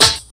Snare (Doomsday).wav